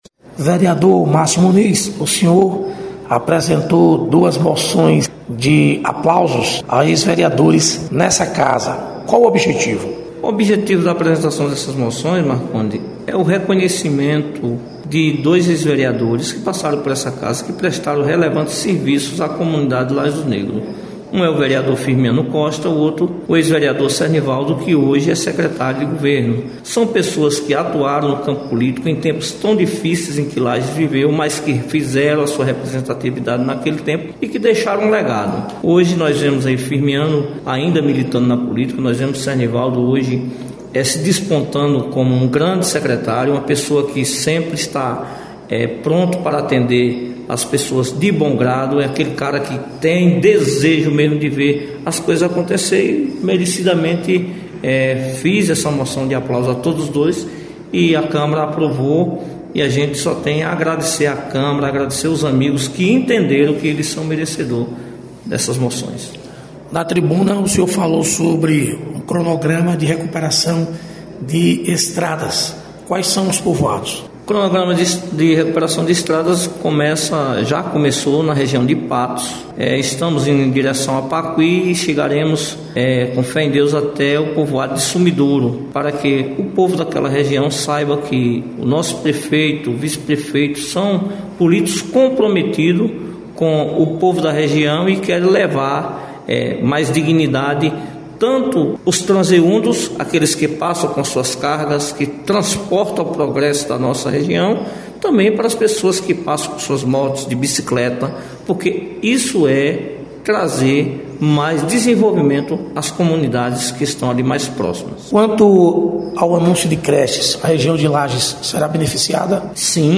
Reportagem: Vereadores de situação e oposição de Campo Formoso